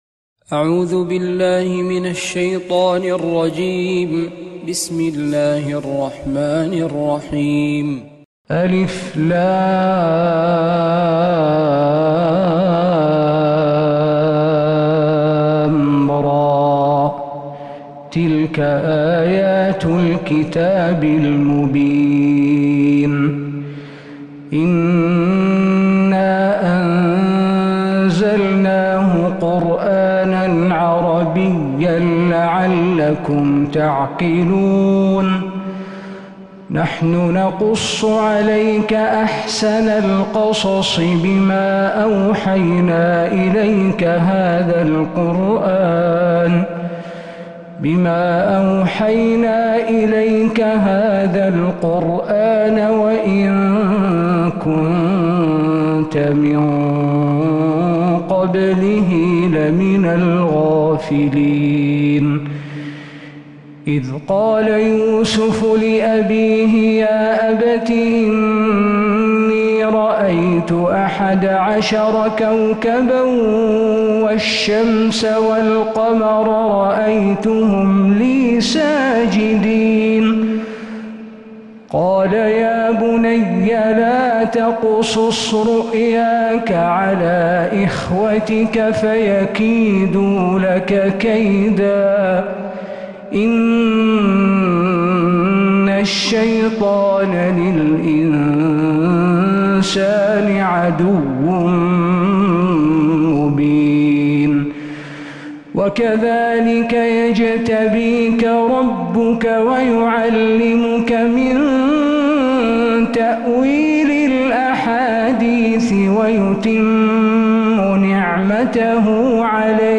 من فجريات الحرم النبوي ١٤٤٧هـ